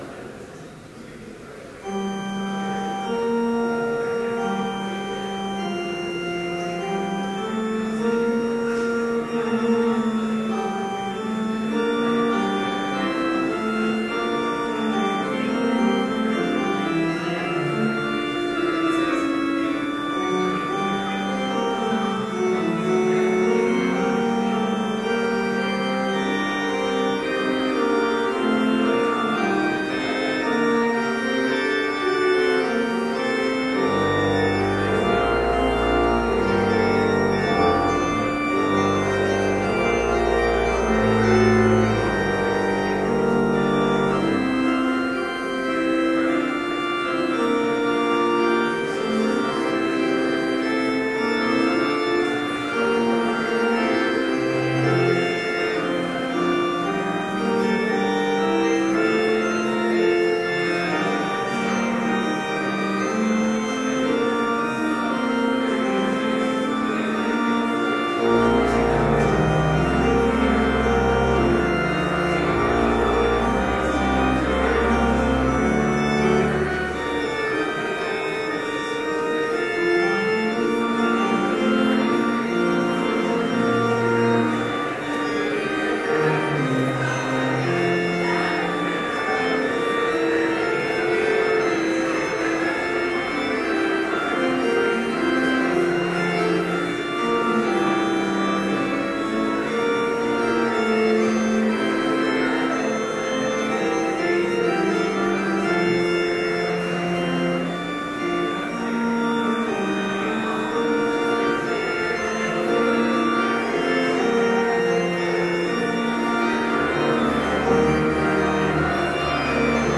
Complete service audio for Chapel - November 11, 2013